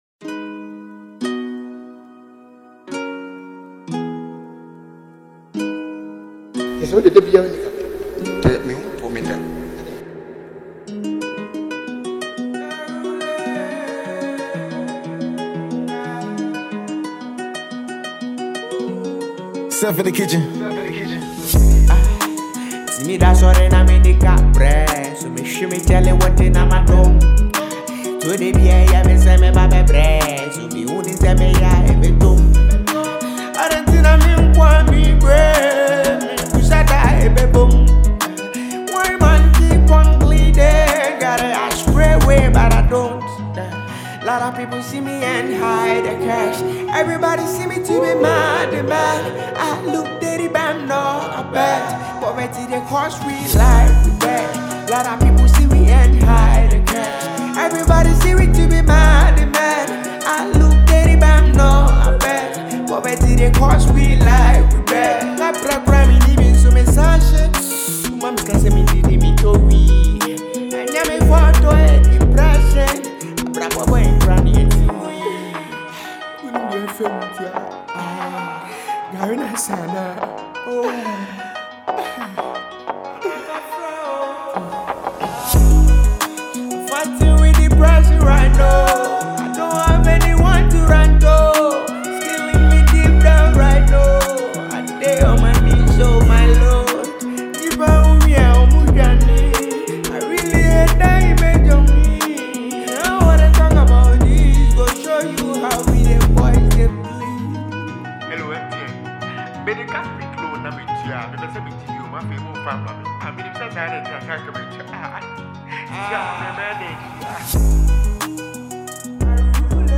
a free mp3 download freestyle for all.